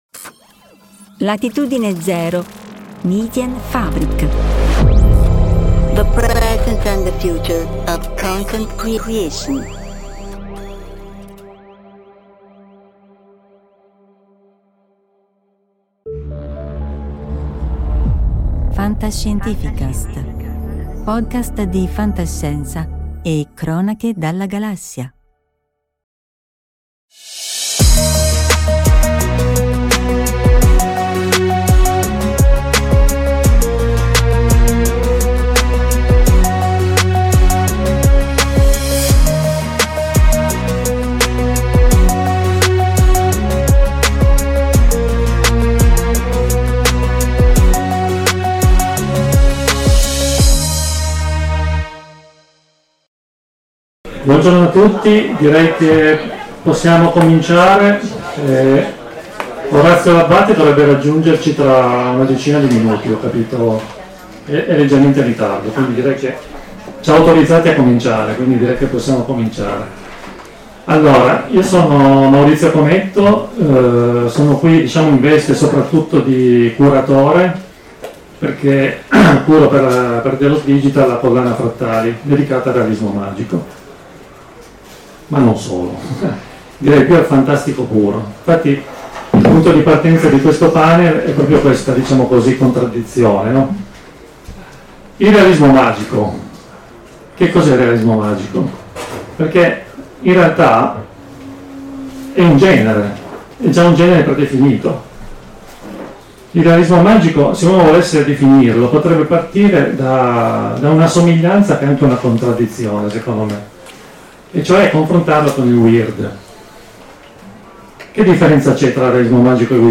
Realismo magico - Stranimondi 2025